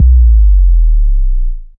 Hard 808 Down Sweep.wav